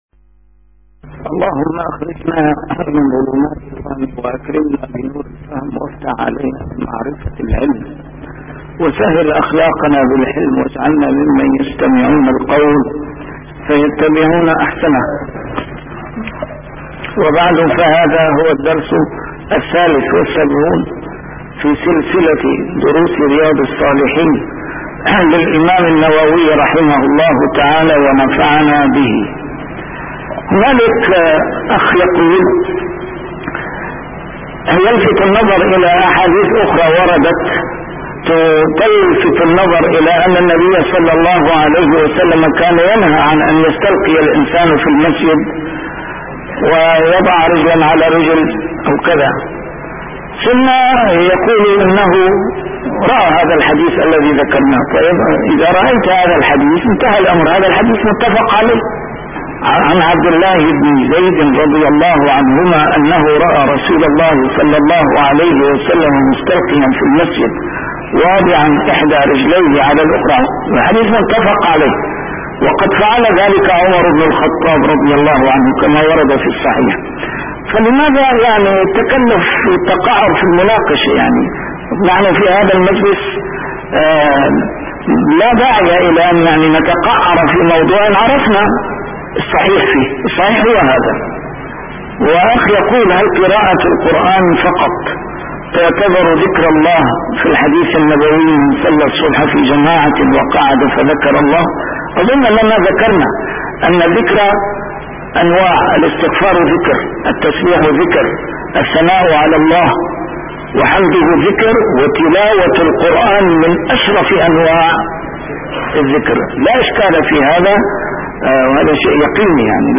A MARTYR SCHOLAR: IMAM MUHAMMAD SAEED RAMADAN AL-BOUTI - الدروس العلمية - شرح كتاب رياض الصالحين - 673- شرح رياض الصالحين: جواز الاستلقاء على القفا